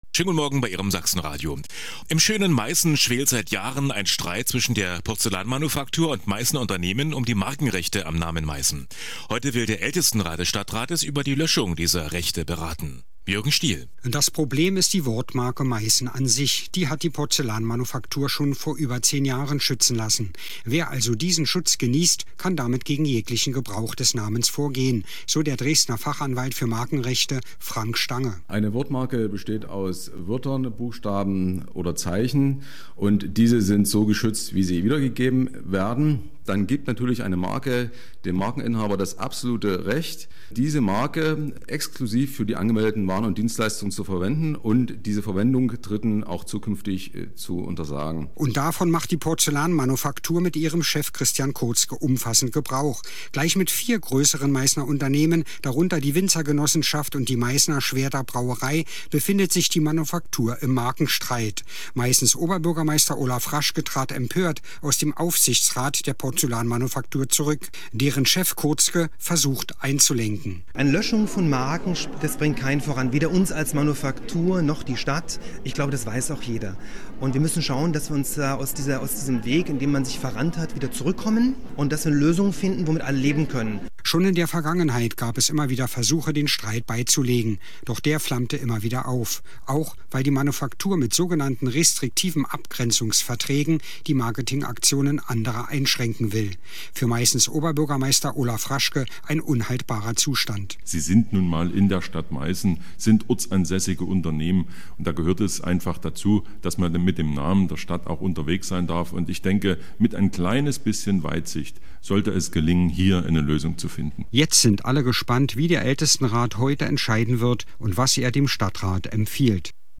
Markenstreit um Meißen geht weiter“ - Radiobeitrag bei mdr 1 Radio Sachsen und mdr info, 11.11.2014,